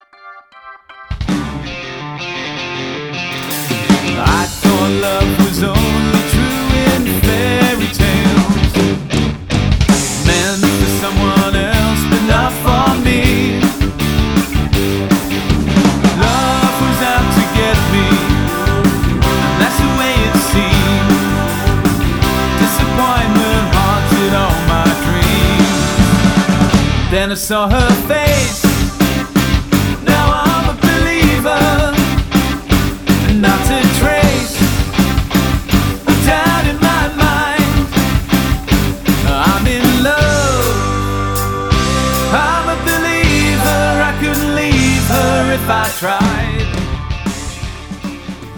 • Versatile 3- to 9-piece party band